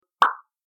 pop_sfx.mp3